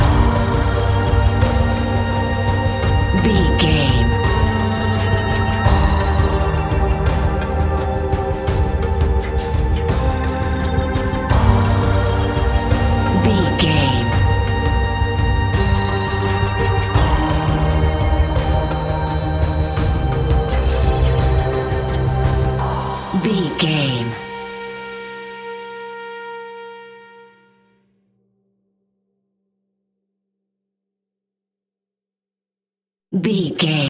Aeolian/Minor
ominous
dark
eerie
synthesizer
drum machine
ticking
electronic music